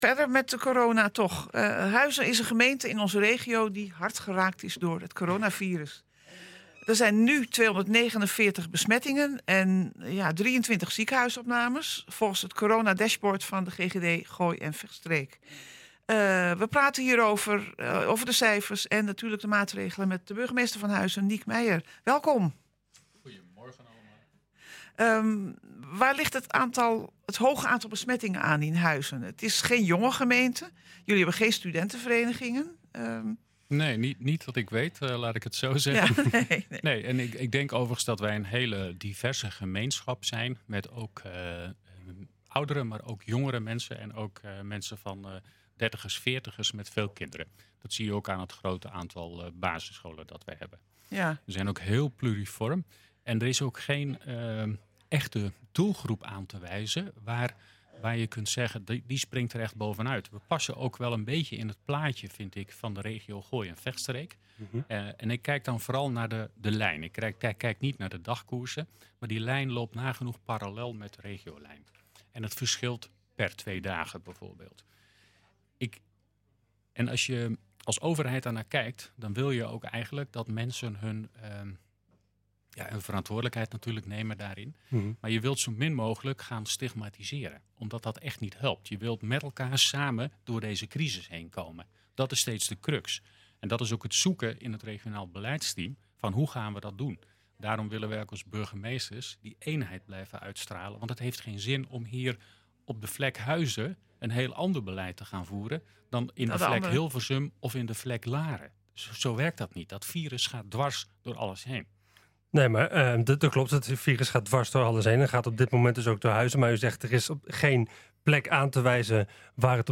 Dat zegt de burgemeester in het radioprogramma NH Gooi Zaterdag.
We praten over de cijfers en natuurlijk de maatregelen met de burgemeester van Huizen, Niek Meijer.